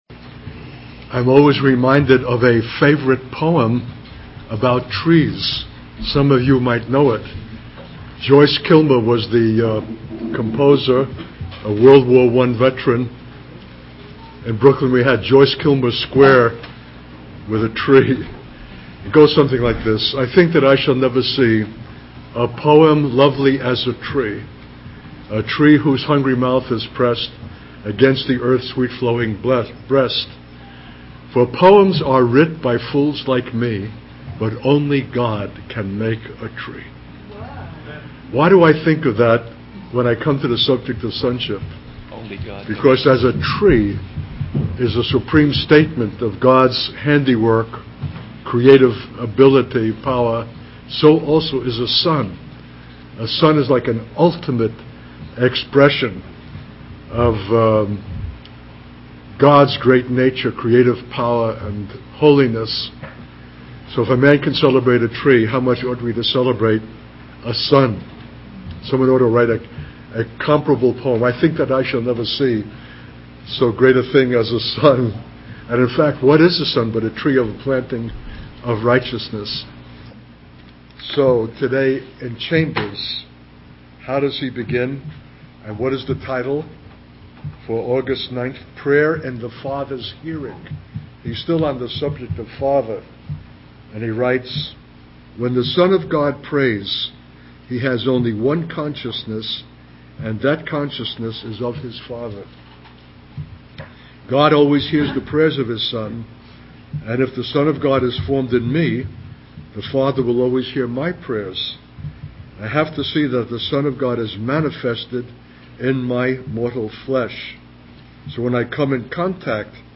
In this sermon, the speaker discusses the importance of understanding and interpreting the scriptures to protect ourselves from deception.